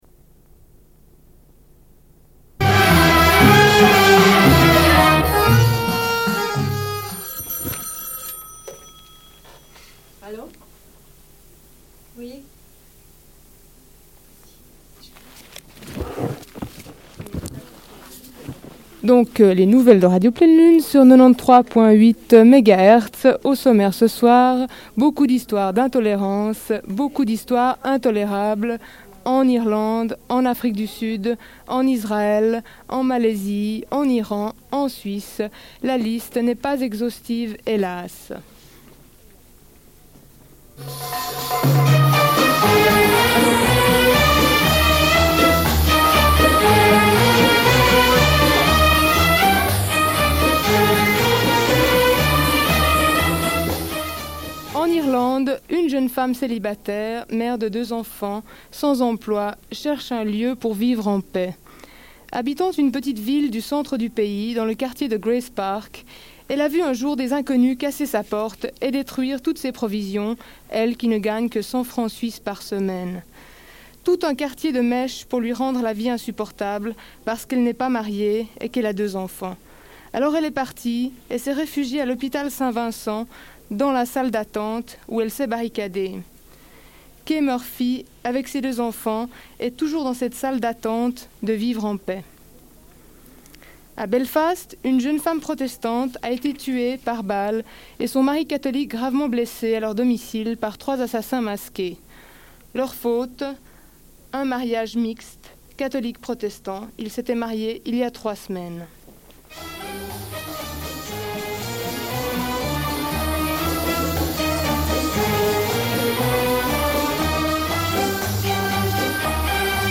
Bulletin d'information de Radio Pleine Lune du 21.06.1986, partie 1 - Archives contestataires
Une cassette audio, face A31:22